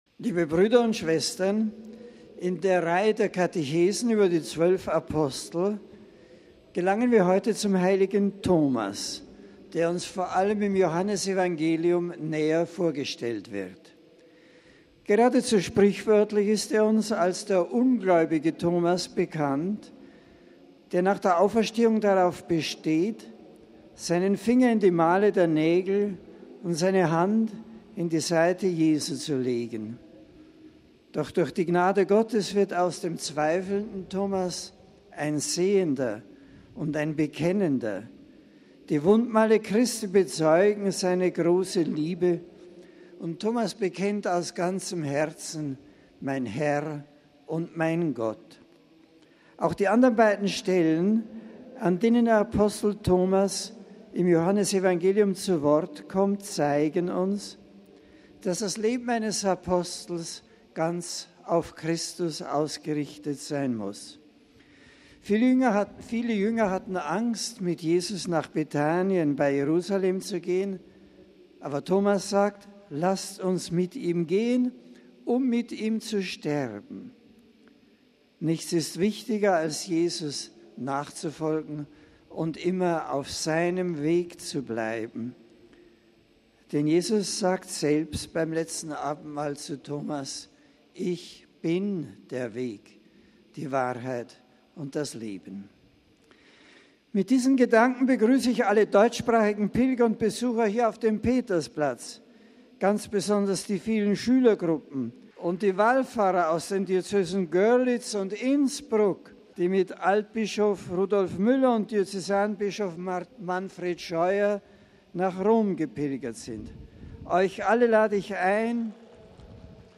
MP3 Papst Benedikt XVI. hat bei der Generalaudienz heute die Betrachtungen über die Apostel wieder aufgenommen. Er sprach vor mehreren zehntausend Gläubigen über den "ungläubigen Thomas".
Der Papst grüßte in zwölf Sprachen.